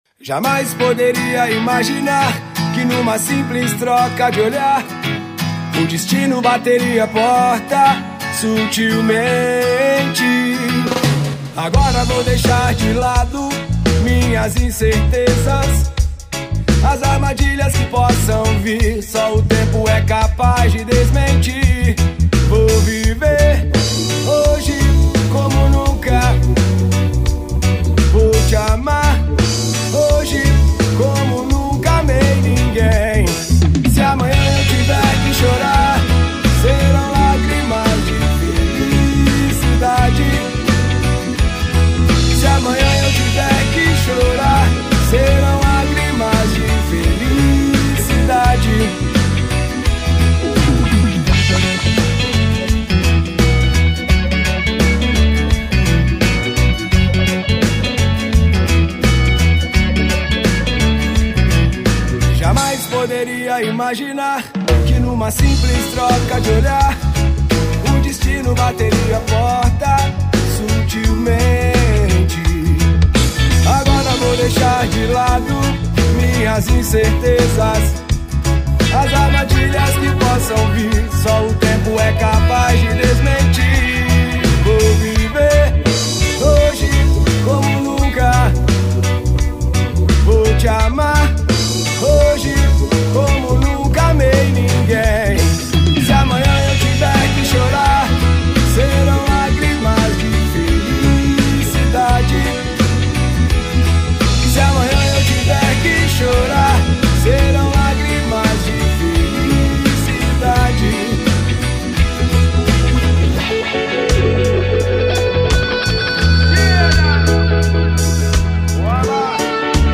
vocal
Guitarra
bateria
baixo
banda de reggae, pop, rock e ska